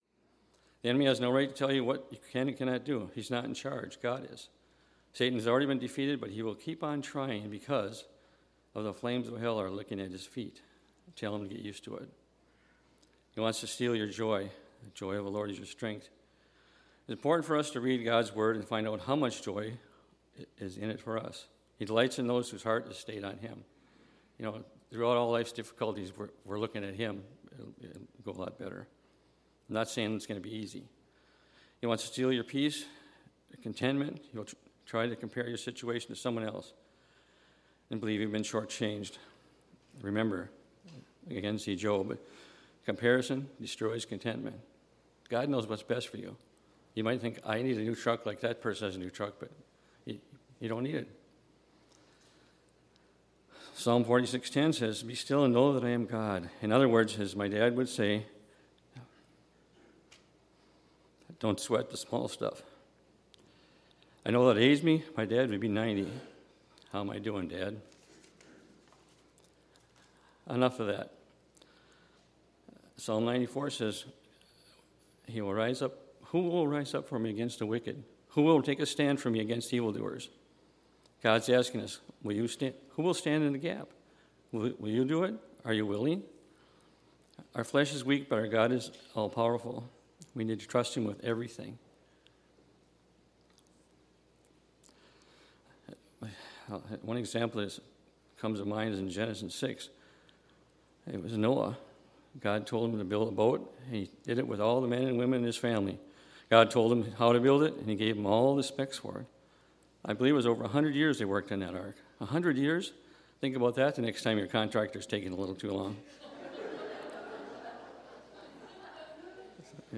Hear from three of our Elders as they share insight from Scripture to encourage us to be faithful and stand firm in these days in which we live.